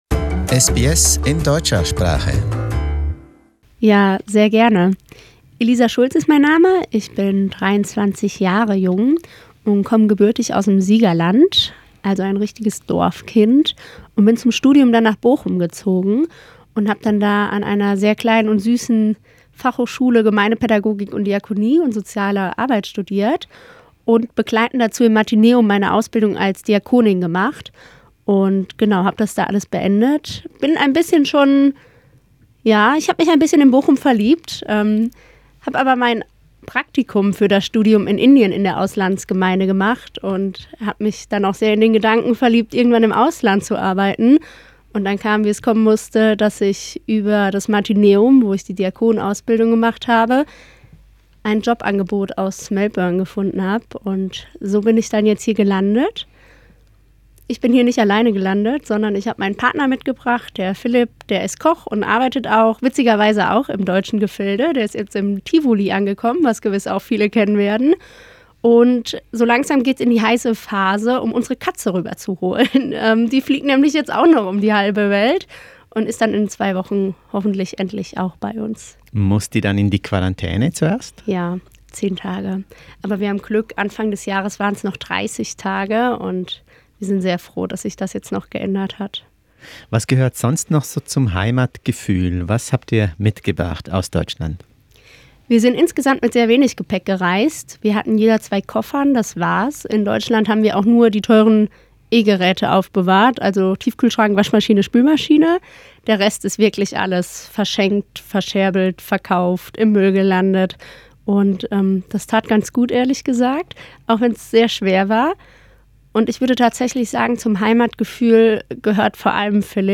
Was hält sie vom Besucherschwund in den christlichen Kirchen? Ein SBS Studiogespräch.